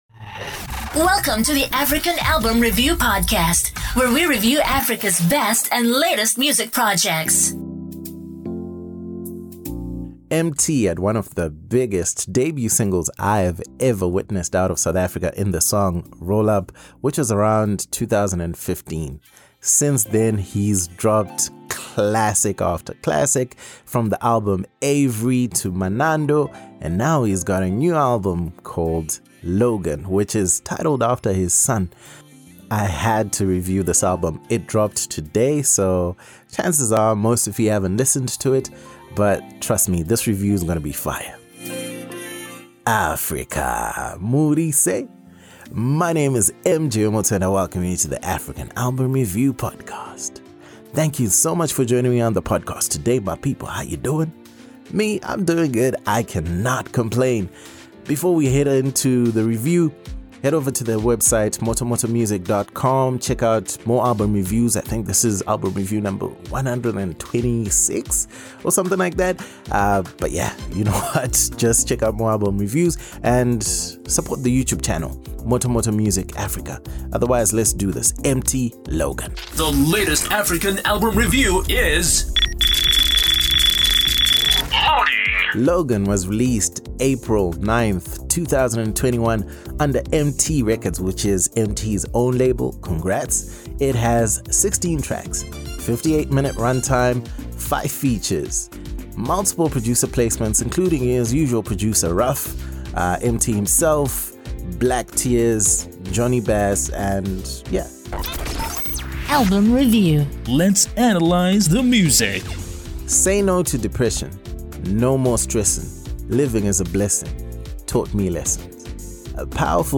Here is my in-depth review and analysis of the new album Logan by Emtee.